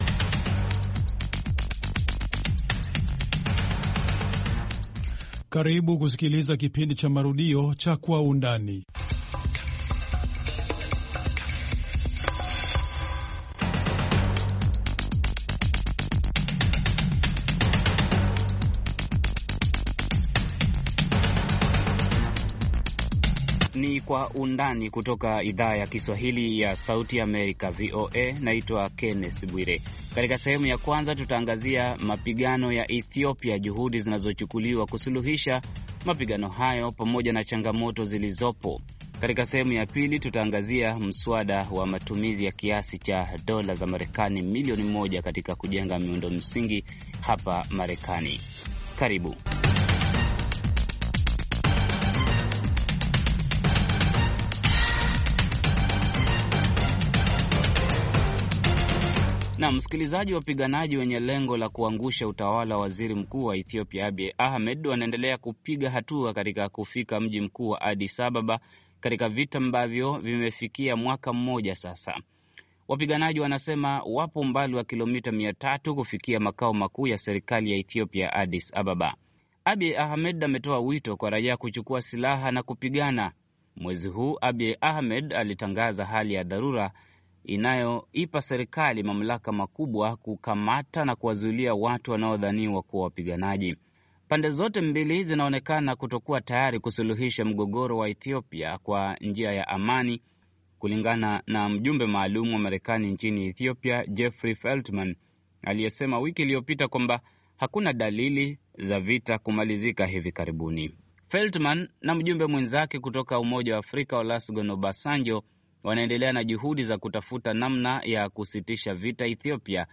Je Nifanyeje? Maamuzi ya Afya na Kijamii kwa Vijana - Kipindi cha dakika 30 kinacholenga vijana kwa kufuatulia maisha ya vijana, hasa wasichana, kuwasaidia kufanya maamuzi mazuri ya kiafya na kijamii ambayo yanaweza kuwa na maana katika maisha yao milele. Kipindi hiki kina sehemu ya habari za afya, majadiliano, na maswali na majibu kwa madaktari na wataalam wengine.